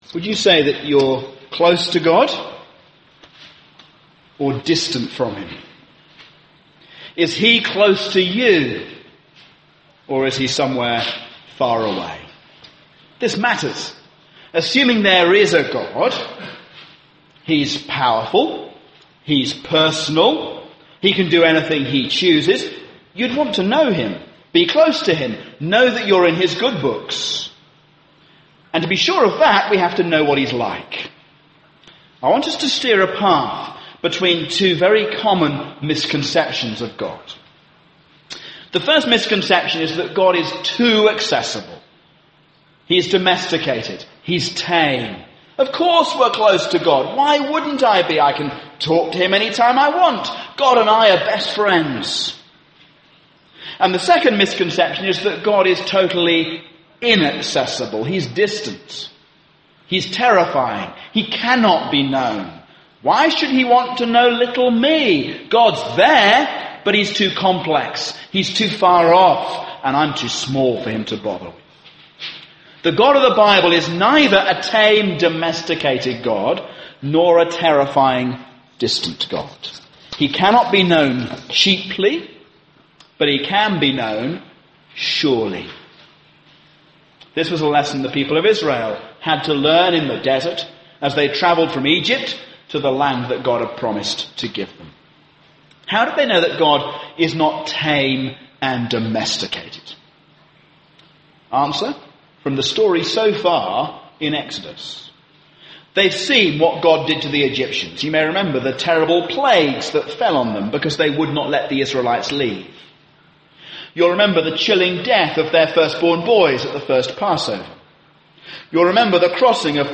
A sermon on Exodus 28:1-14